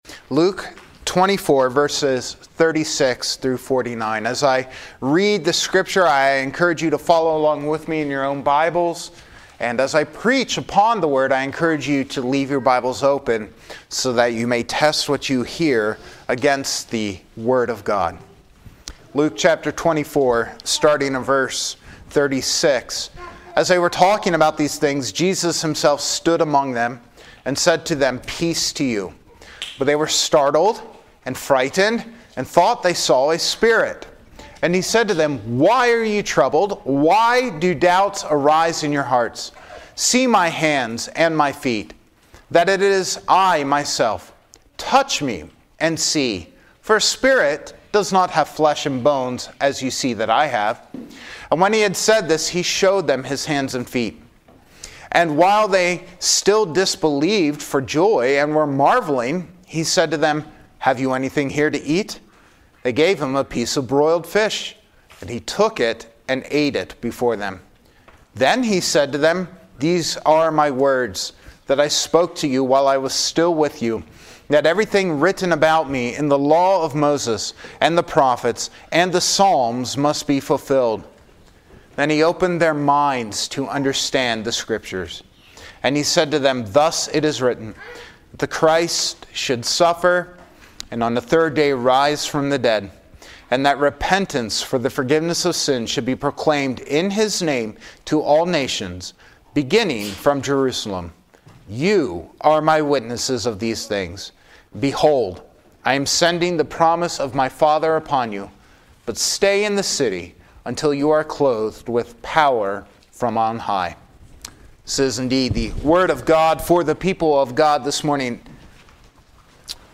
Easter Sunday 2025 (Luke 24:36-49)